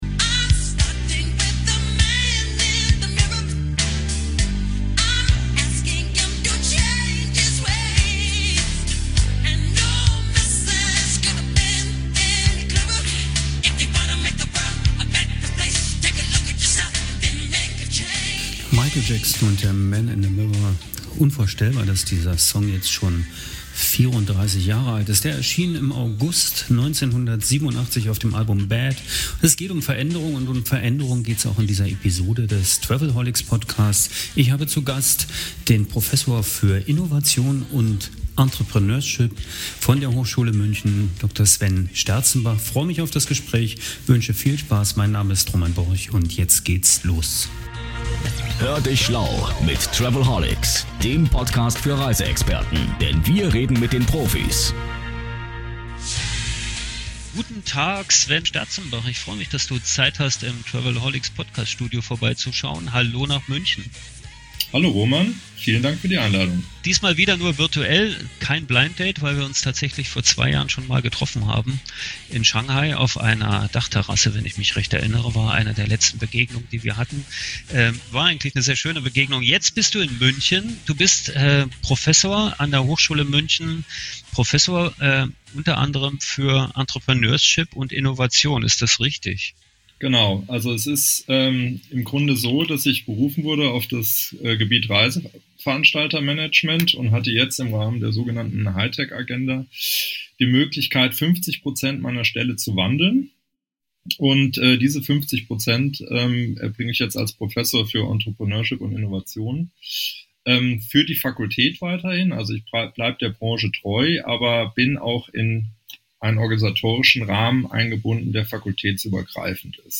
Und warum pumpen Investoren Milliarden in touristische Plattformen, die noch nie Gewinn machten? Dies sind nur einige der Fragen an meinen Gast im travelholics Podcast Studio.